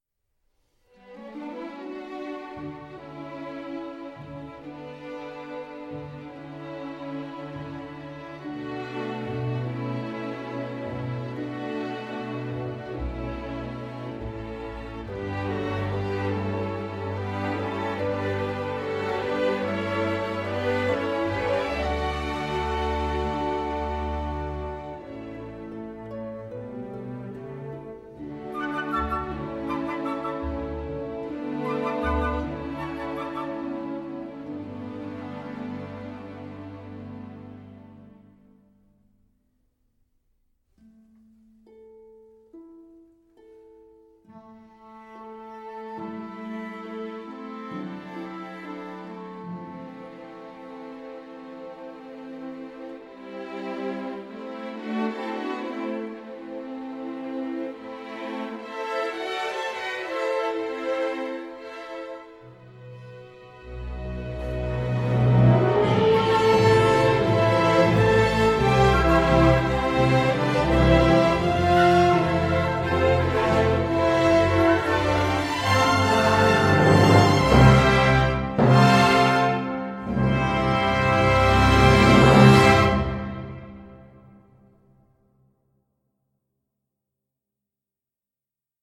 c’est très classique, rien de révolutionnaire